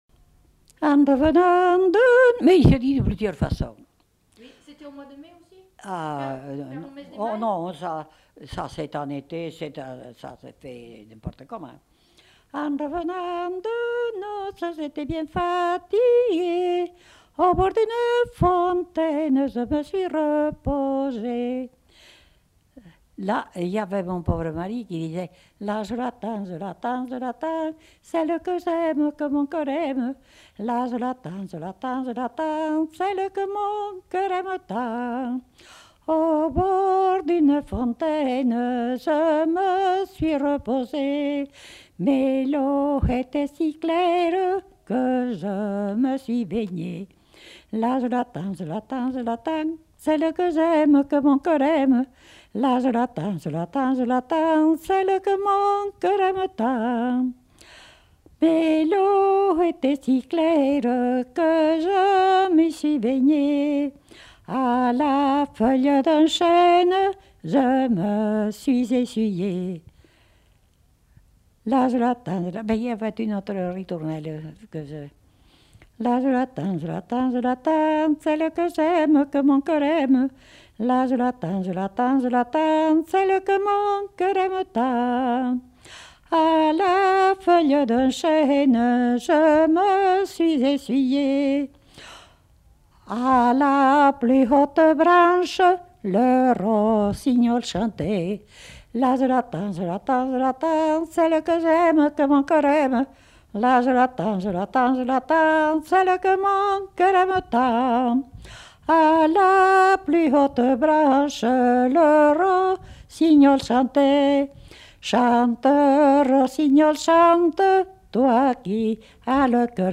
Aire culturelle : Haut-Agenais
Lieu : Condezaygues
Genre : chant
Effectif : 1
Type de voix : voix de femme
Production du son : chanté ; récité